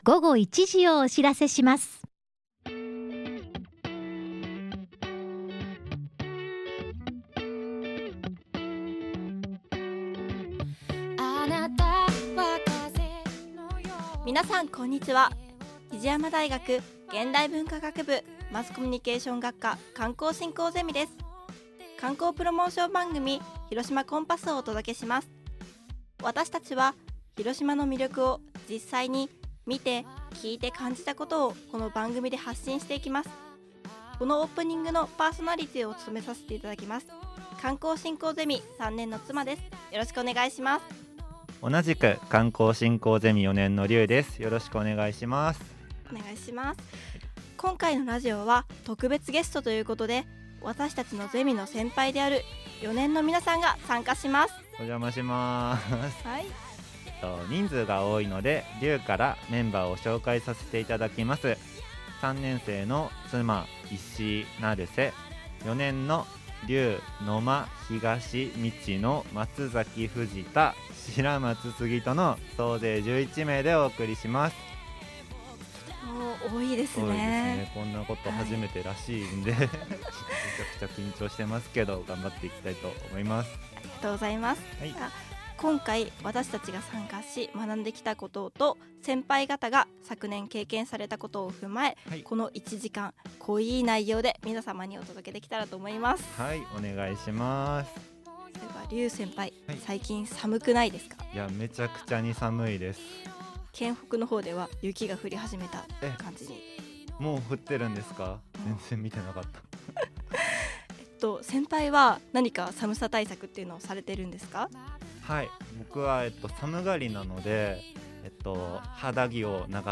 昨年レギュラーの4年生と今年度レギュラー3年生合同パーソナリティ登場。
パーソナリティは過去最高の11名でお届けしました！